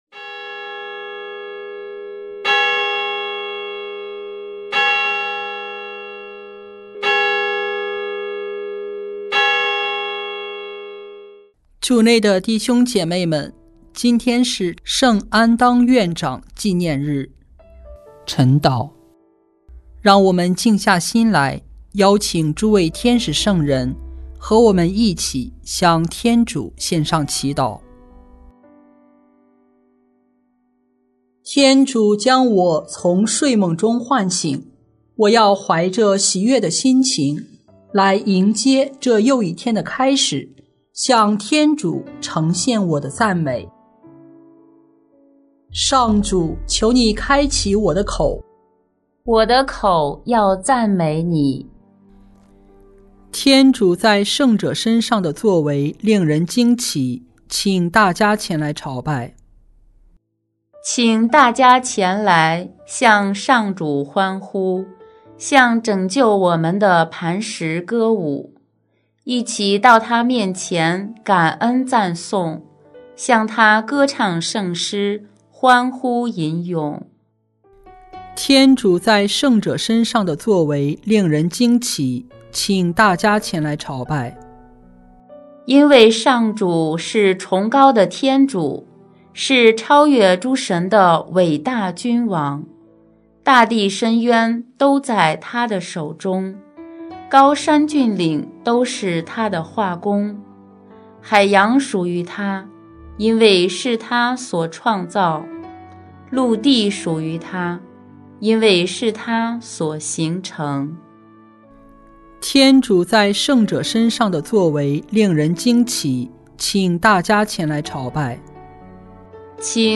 【每日礼赞】|1月17日圣安当院长纪念日晨祷（第一周周六）